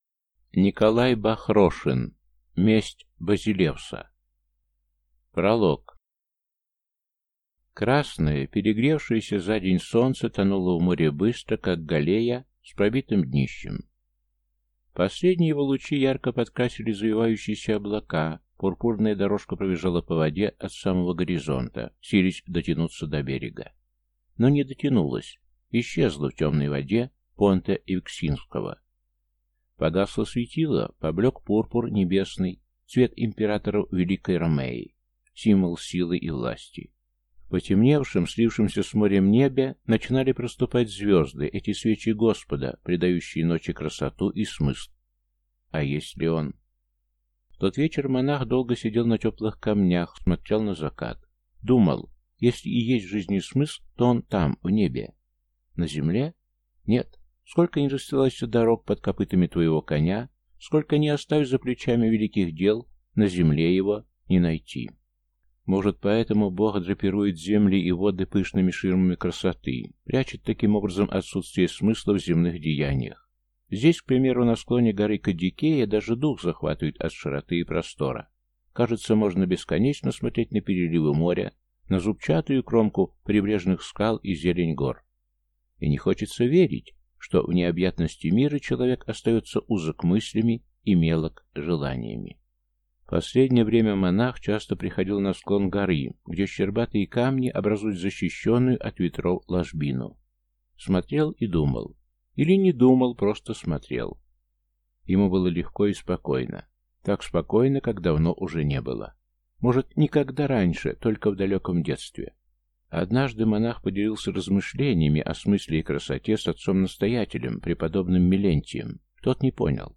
Аудиокнига Месть базилевса | Библиотека аудиокниг